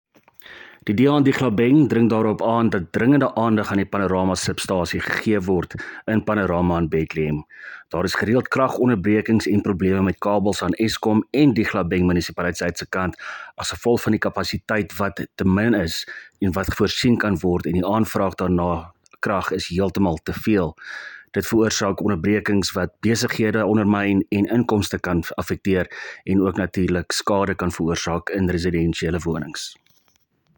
Afrikaans soundbites by Cllr Willie Theunissen and